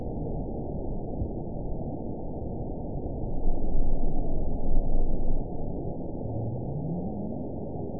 event 920970 date 04/21/24 time 00:14:47 GMT (1 year, 1 month ago) score 9.48 location TSS-AB05 detected by nrw target species NRW annotations +NRW Spectrogram: Frequency (kHz) vs. Time (s) audio not available .wav